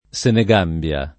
vai all'elenco alfabetico delle voci ingrandisci il carattere 100% rimpicciolisci il carattere stampa invia tramite posta elettronica codividi su Facebook Senegambia [ S ene g# mb L a ] top. f. (Afr.) — confederazione tra Gambia e Senegal (1982-89)